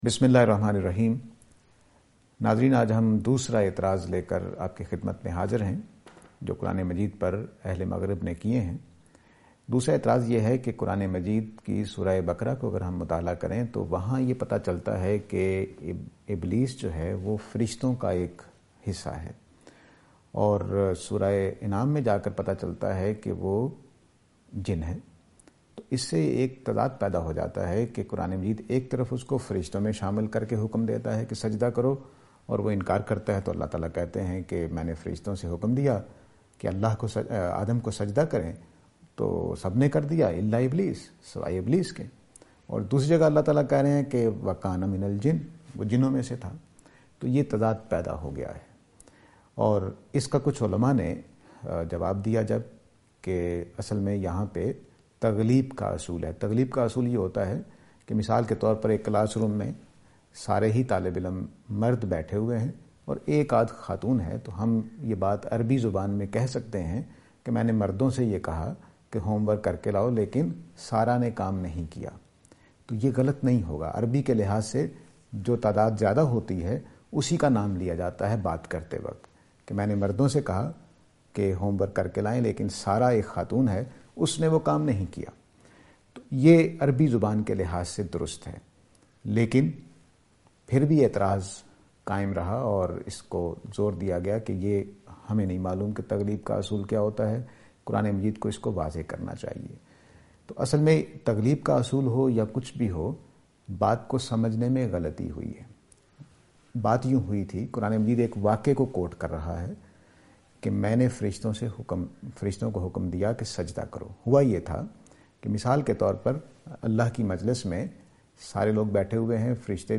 This lecture will present and answer to the allegation "Satin, Jins and Angels".